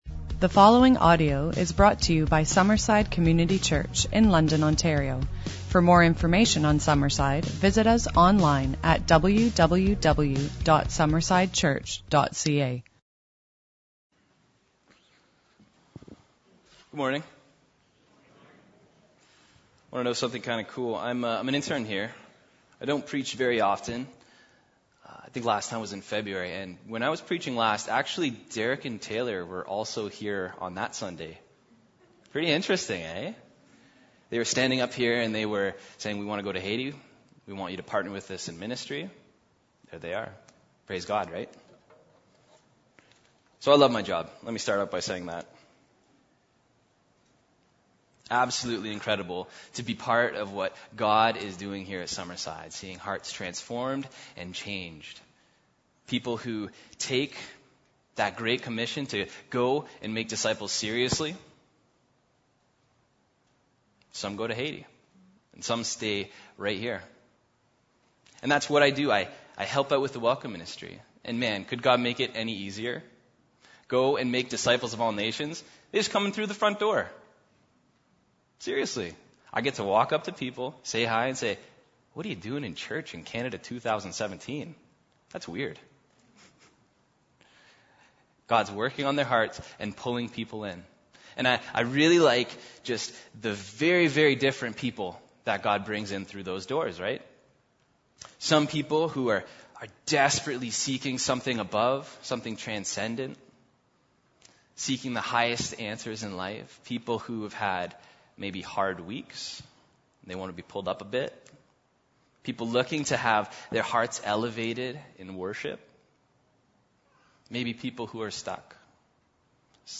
This week’s sermon deals with the intense tension of the Christian walk. We are encountering God’s word for us from Philippians 3:12-16, learning that we strive upward by letting go and holding on.